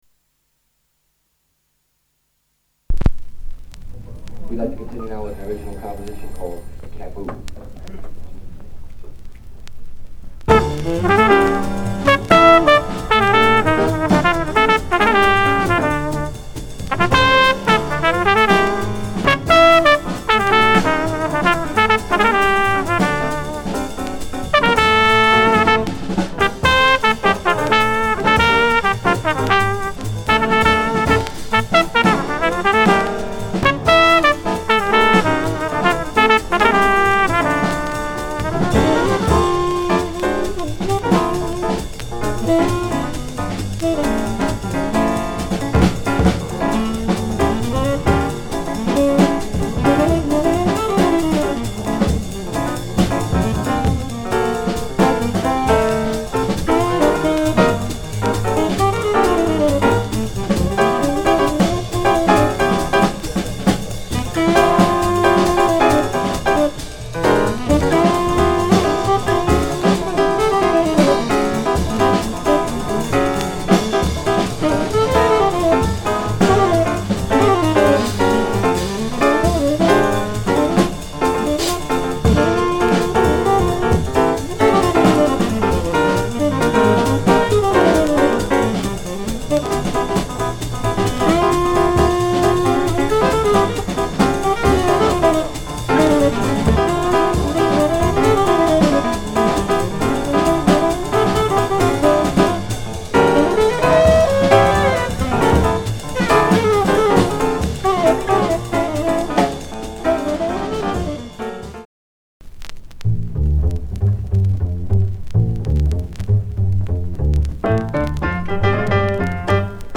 discription:Stereo黒金DG
盤面に音に影響ないスリキズのみで再生良好です。